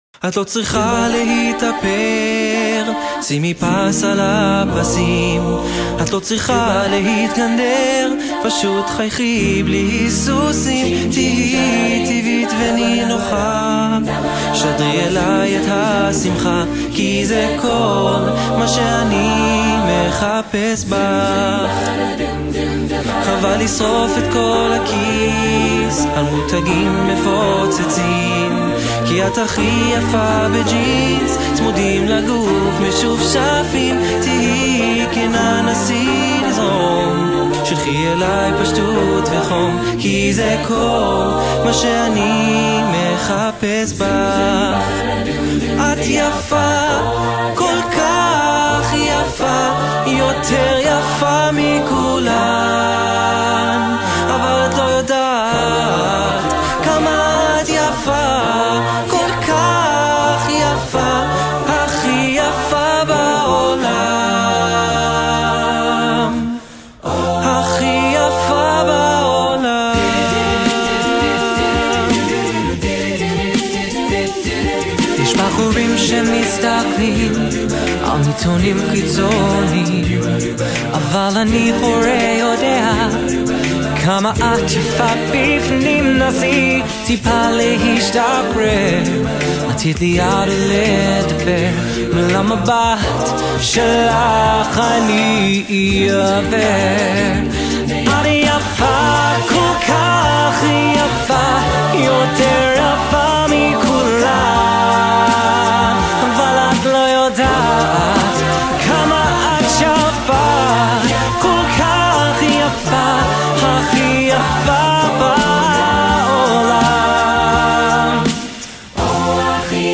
Genre: Israeli
Contains solos: Yes